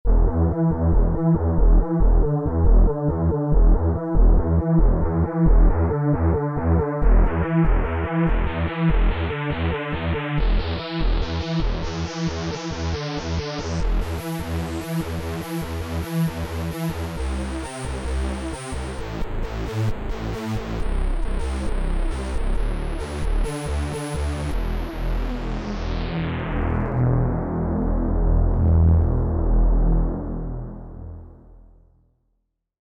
:play Yamaha TX16W 16 x Sawth:
yamaha_tx16w_-_sounds_demo_-_16xsawth.mp3